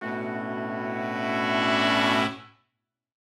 Index of /musicradar/gangster-sting-samples/Chord Hits/Horn Swells
GS_HornSwell-Bmin+9sus4.wav